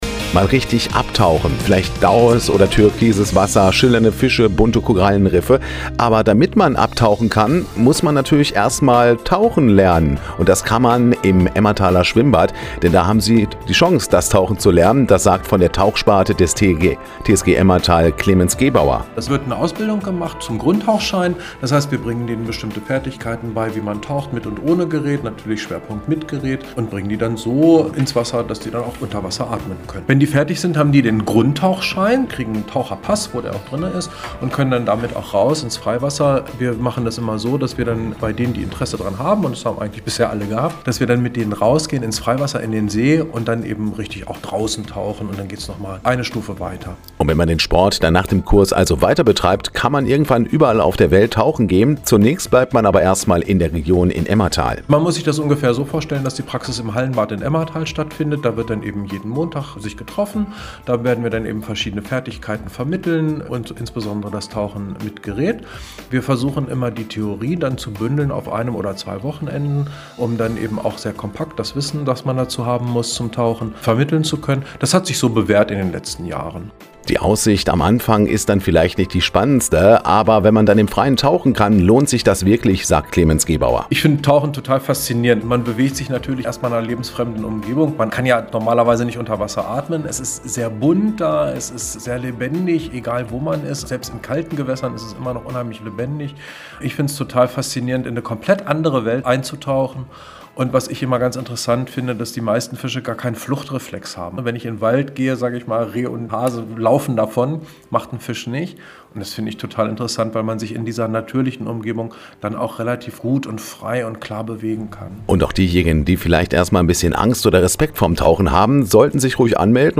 Interview radio aktiv
187-interview-radio-aktiv.mp3